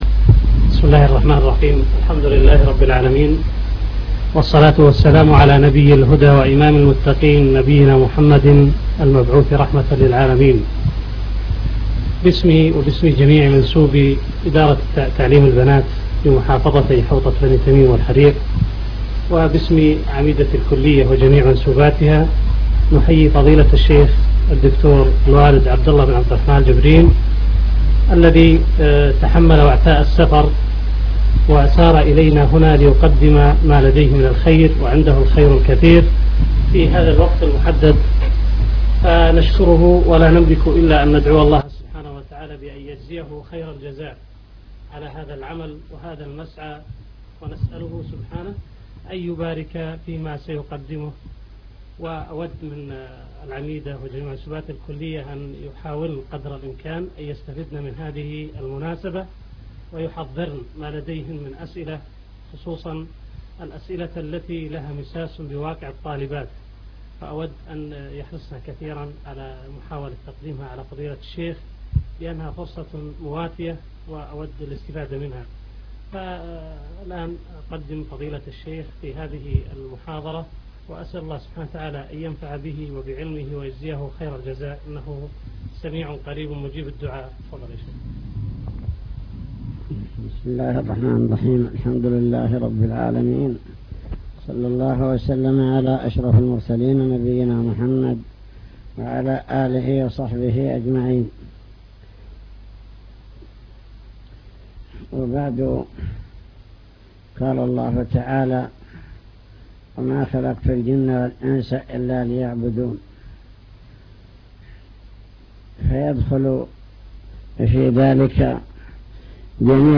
المكتبة الصوتية  تسجيلات - محاضرات ودروس  محاضرة بكلية البنات